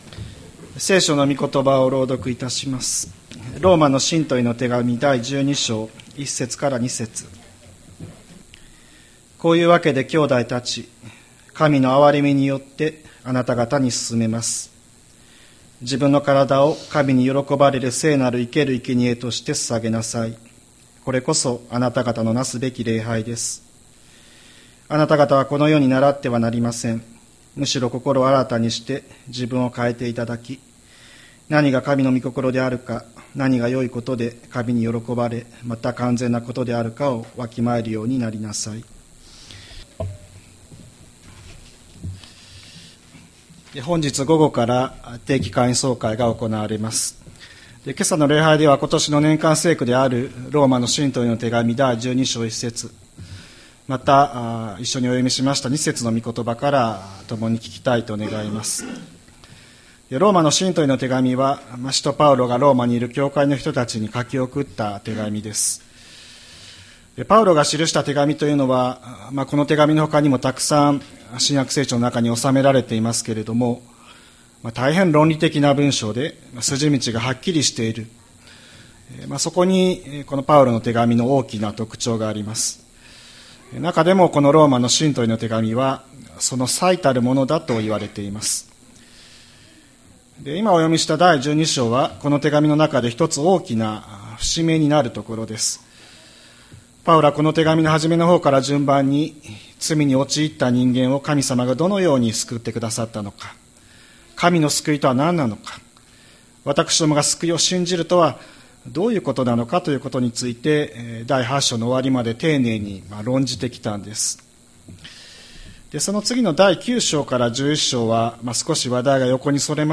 2026年01月25日朝の礼拝「献身」吹田市千里山のキリスト教会
千里山教会 2026年01月25日の礼拝メッセージ。